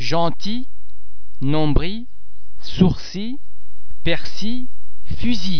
BUT silent in